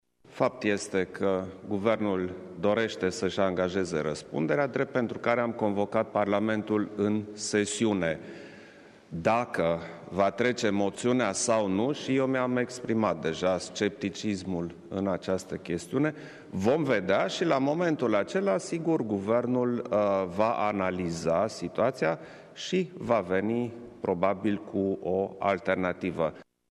Referitor la moțiunea inițiată de PSD la adresa Guvernului, care susține că a adunat numărul de voturi necesar, șeful statului a declarat:
Iohannis-Parlament.mp3